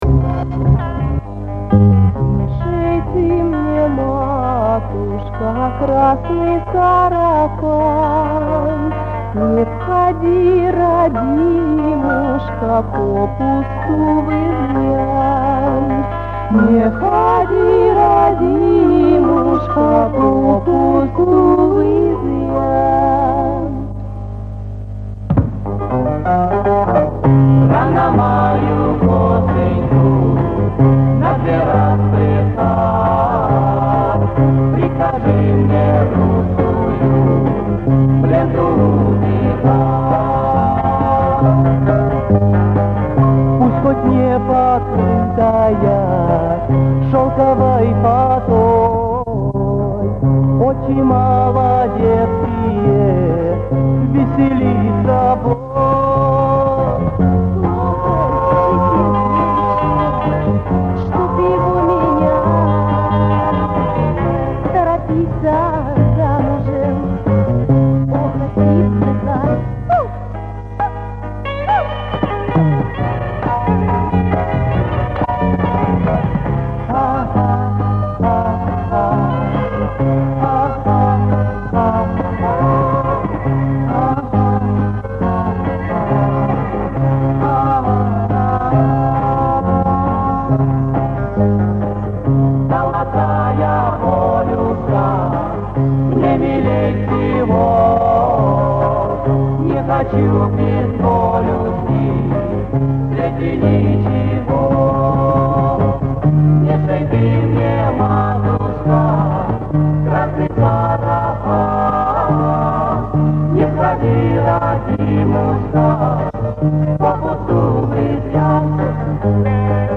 Начинается медленно, без музыкального сопровождения, затем резко звучит гитара и далее в стиле
рок-н-рол.